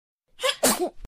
Детский Чих